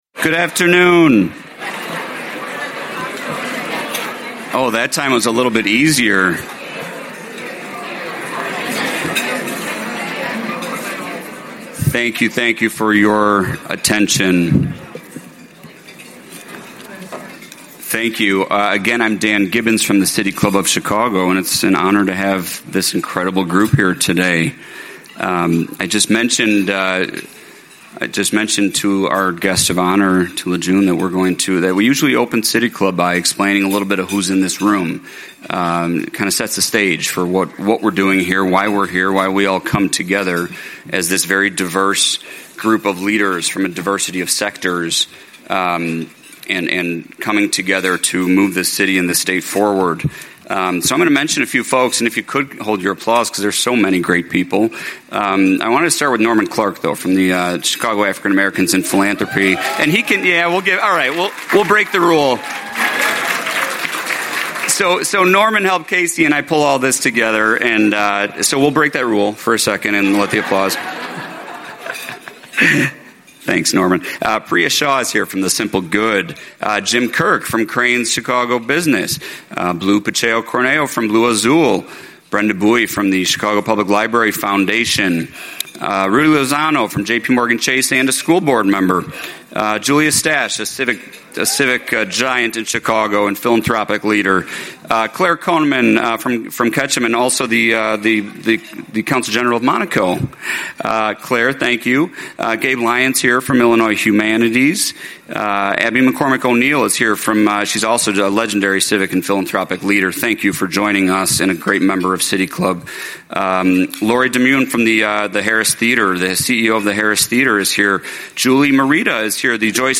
Join the W.K. Kellogg Foundation and The Joint Affinity Group representing philanthropy in Chicago (Chicago African Americans in Philanthropy, Chicago Latines in Philanthropy, and Asian Americans/Pacific Islanders in Philanthropy) for an afternoon of honest conversation and community connection. As polarization deepens and efforts toward diversity, equity, and inclusion face new challenges, we’ll explore how racial healing can help bridge divides and foster solidarity.